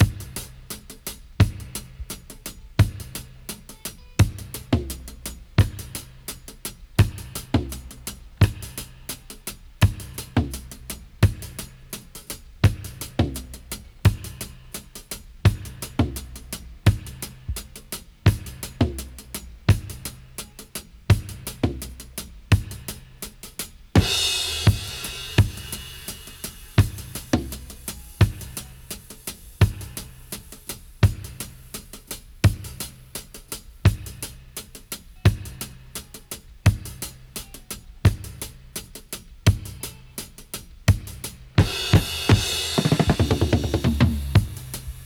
85-FX-04.wav